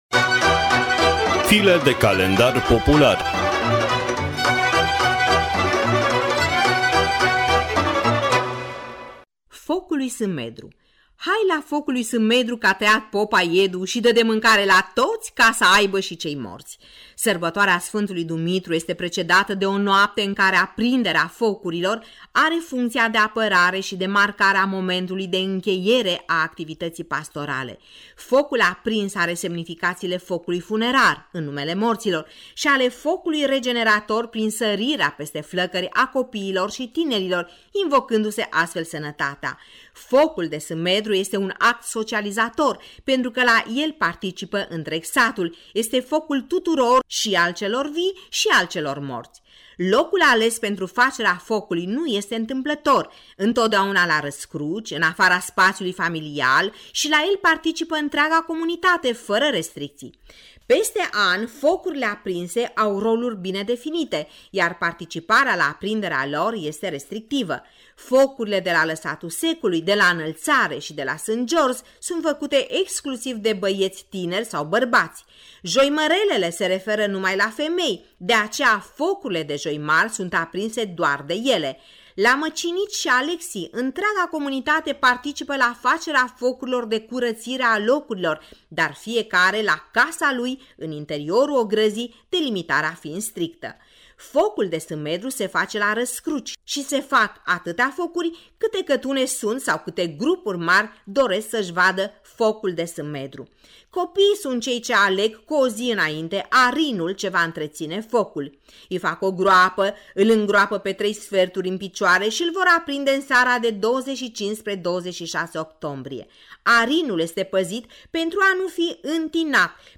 (varianta radiofonică a rubricii)